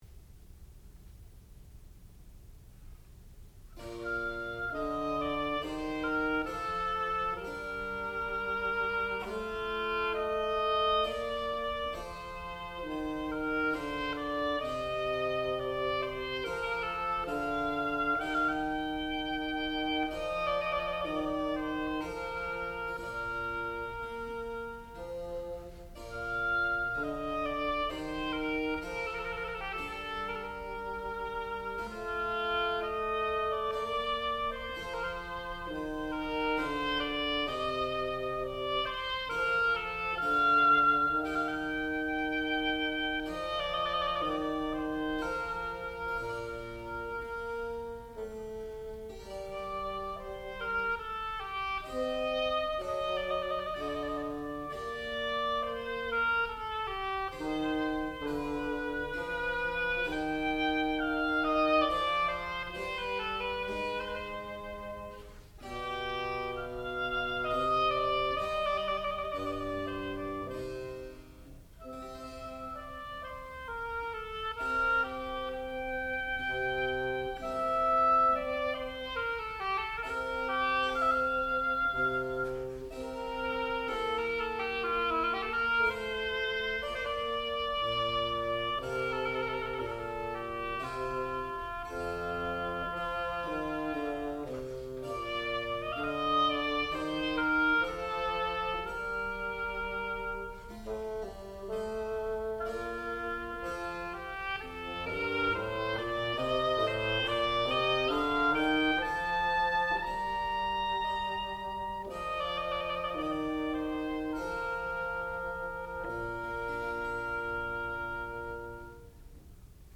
Partita No.1 in B Flat for oboe and continuo
classical music
harpsichord